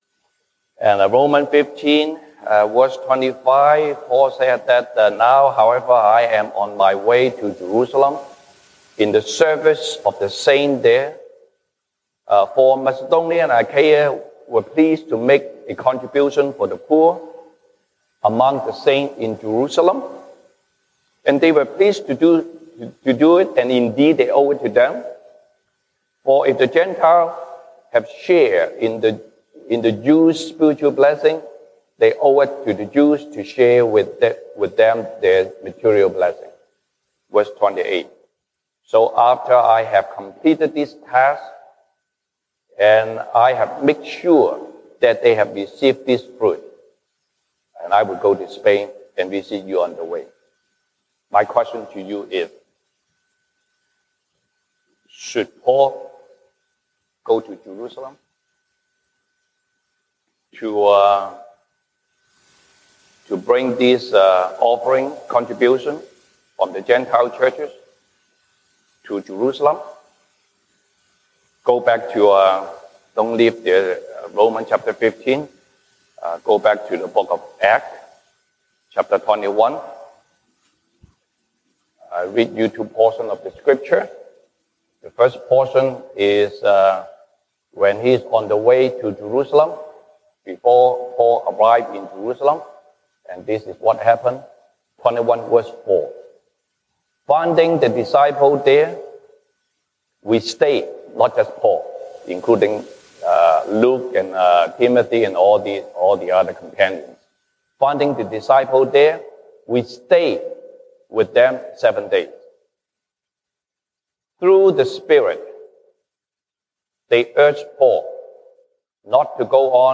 西堂證道 (英語) Sunday Service English: If it is the Lord’s will, we will live and do this or that.